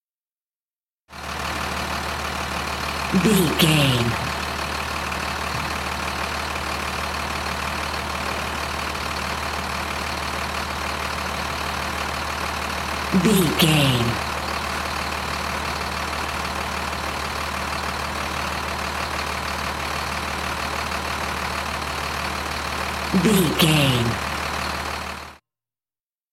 Buss engine idle
Sound Effects